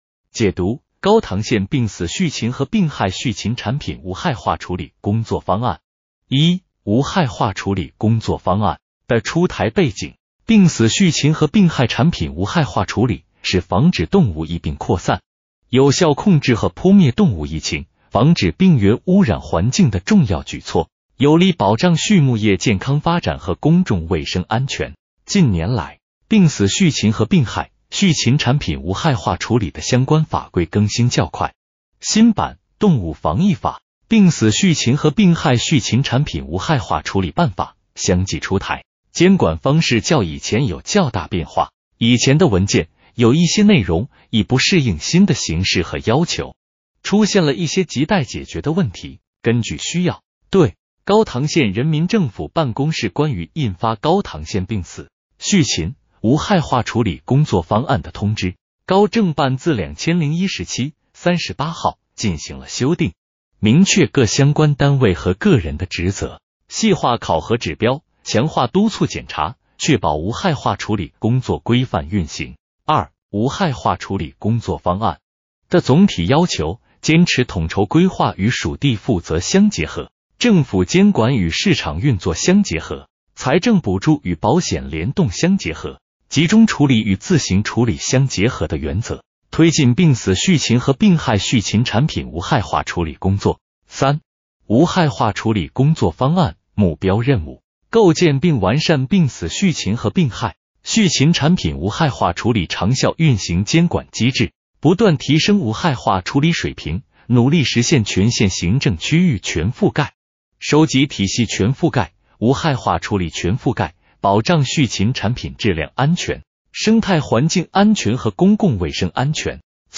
【音频解读】高唐县病死畜禽和病害畜禽产品无害化处理工作方案